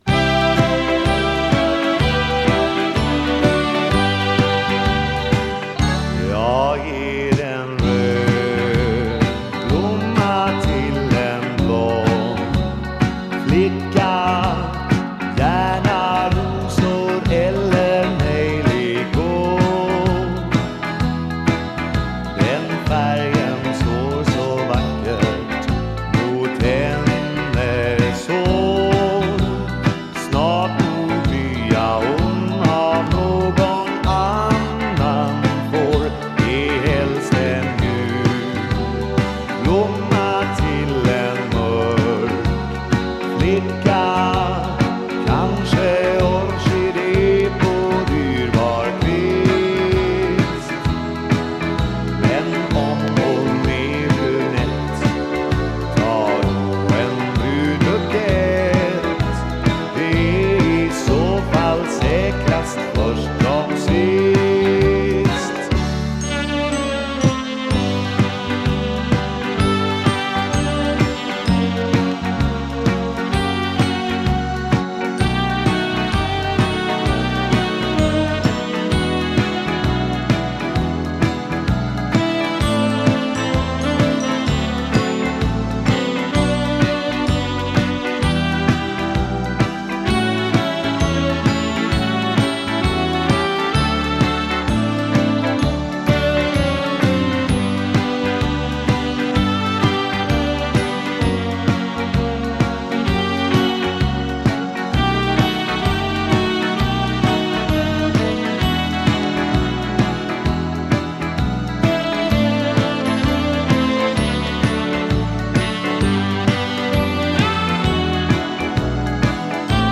Vocals, Piano, Accordion
Bass
Vocals, Drums, Percussion
Vocals, Guitars
Sax